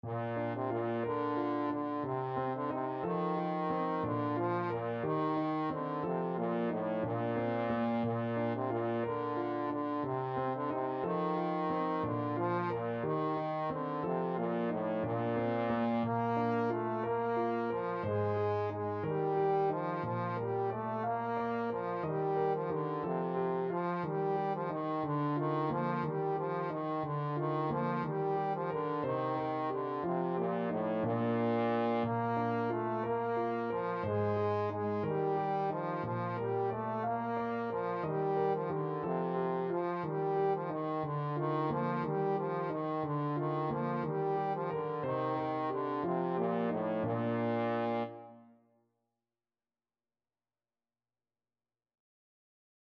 Trombone
Bb major (Sounding Pitch) (View more Bb major Music for Trombone )
6/8 (View more 6/8 Music)
Traditional (View more Traditional Trombone Music)